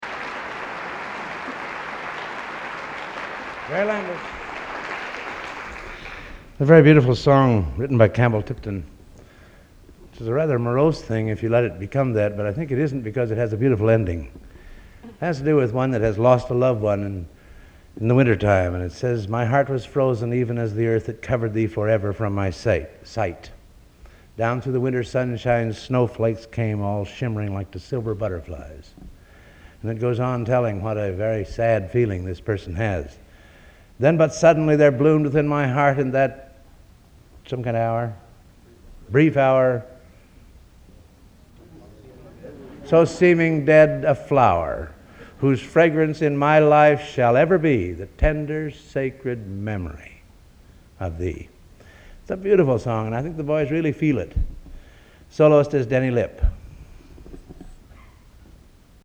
Location: West Lafayette, Indiana
Genre: | Type: Director intros, emceeing |End of Season